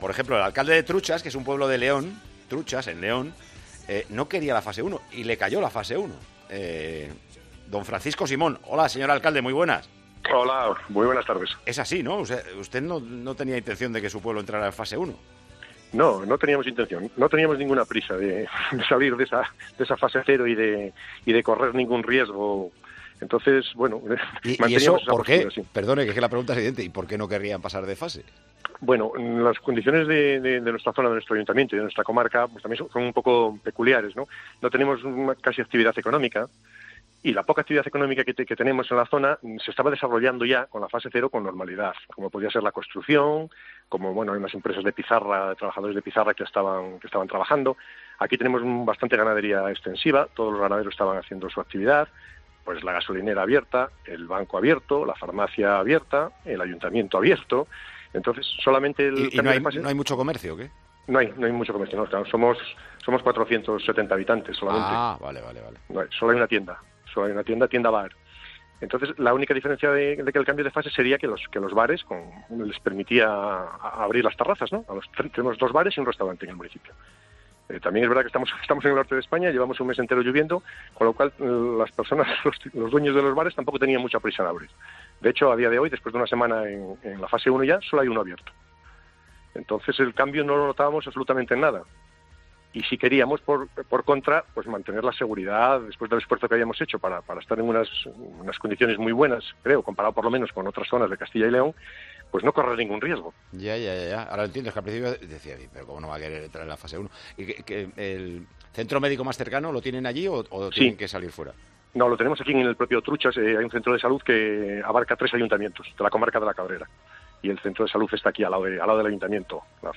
El alcalde de la localidad leonesa nos explica las razones por las que no querían pasar a la siguiente fase de la desescalada.